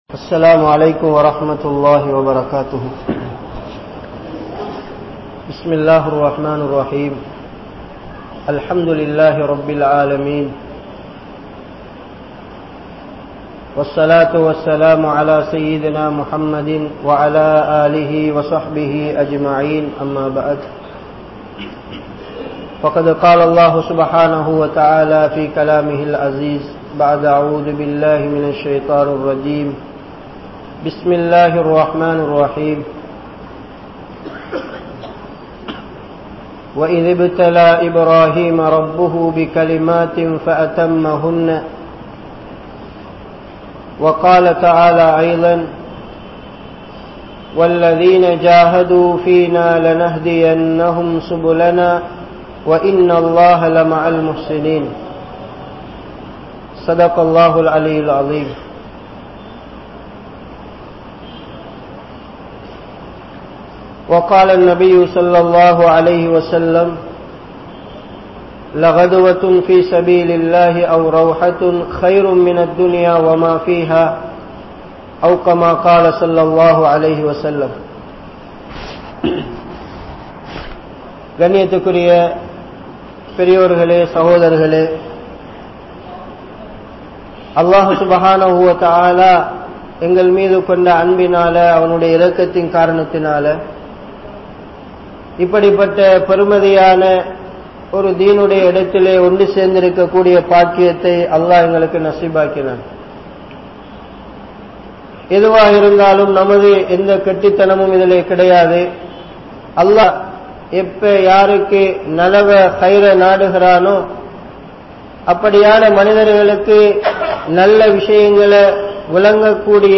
Allah`vin Sakthi (அல்லாஹ்வின் சக்தி) | Audio Bayans | All Ceylon Muslim Youth Community | Addalaichenai